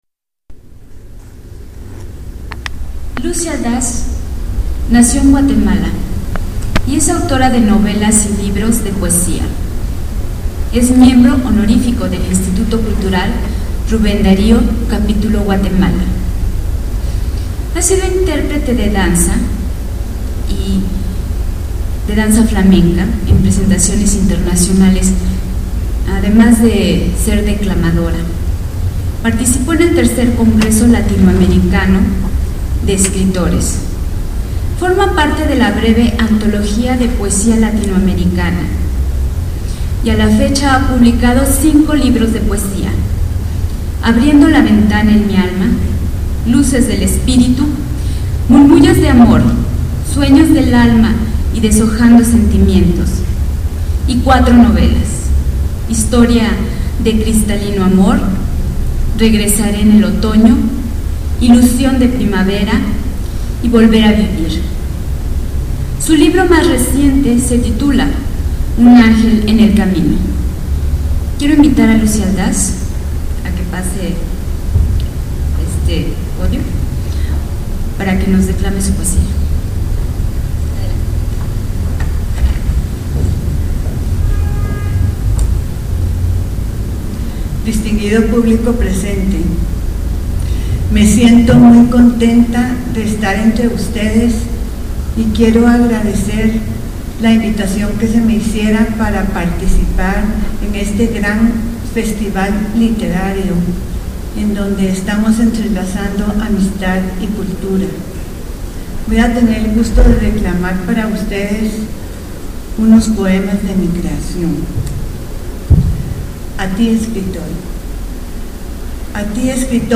Lugar: Teatro Daniel Zebadúa de San Cristóbal de Las Casas, Chiapas.
Equipo: iPod 2Gb con iTalk